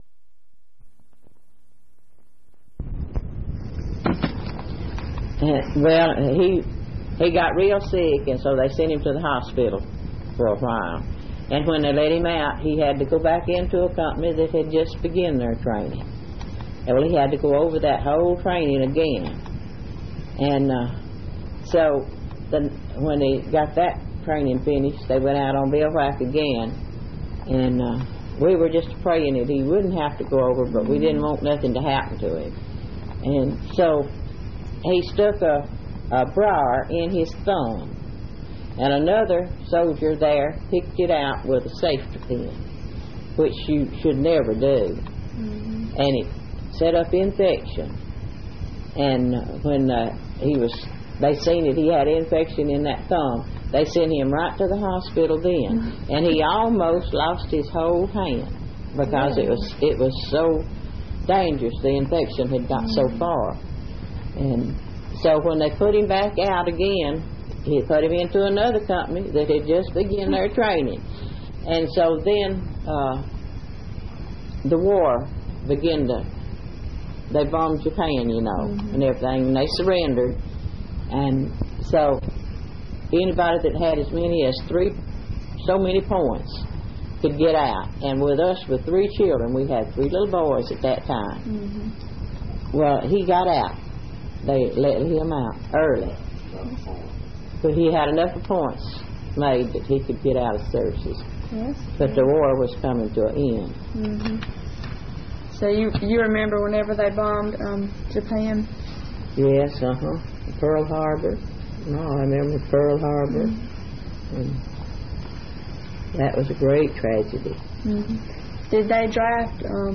Oral History Collection